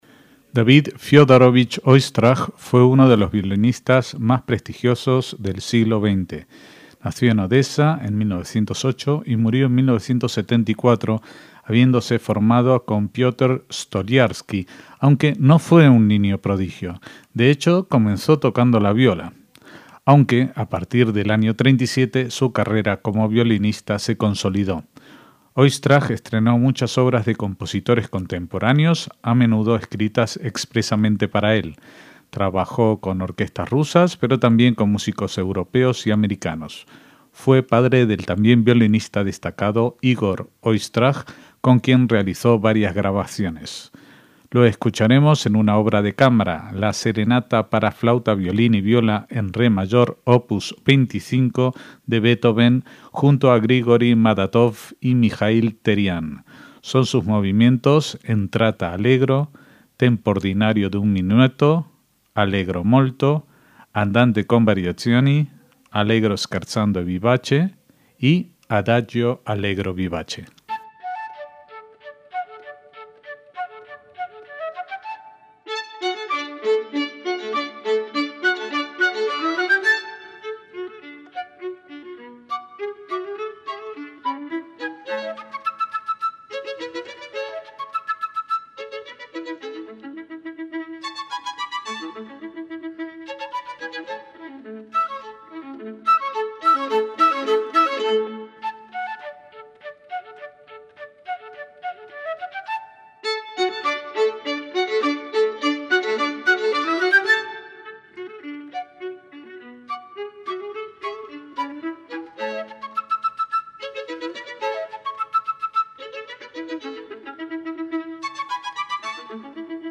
Beethoven 250: David Oistrakh y la Serenata para flauta, violín y viola, opus 25
MÚSICA CLÁSICA
en re mayor para flauta, violín y viola